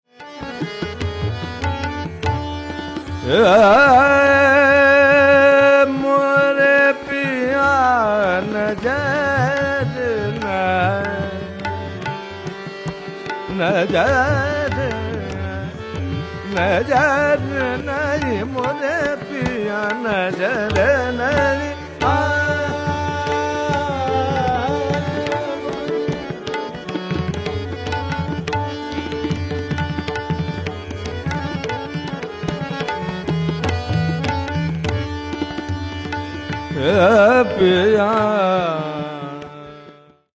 S-rR-gG-mM-P-D-nN-S
• Tanpura: Sa–Pa